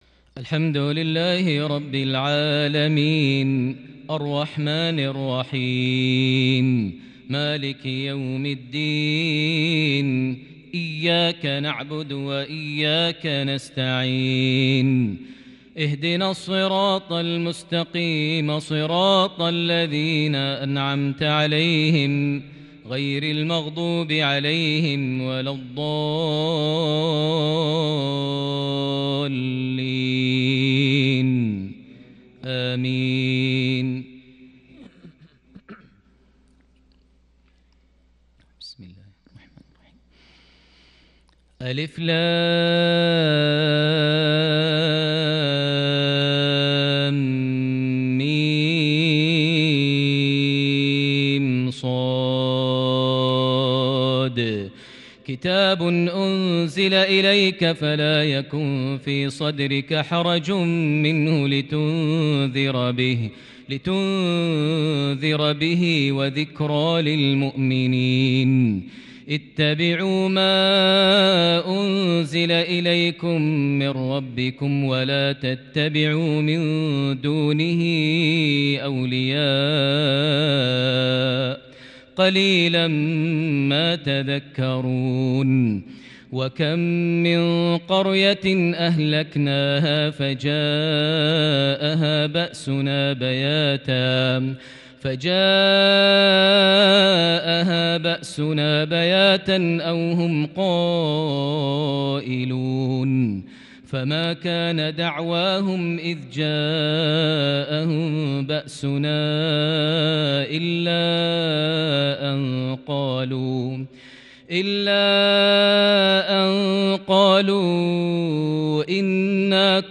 عشائية تتجلّى بالإبداع من سورة الأعراف لقصة آدم عليه السلام (1-18) | 23 جمادى الآخر 1442هـ > 1442 هـ > الفروض - تلاوات ماهر المعيقلي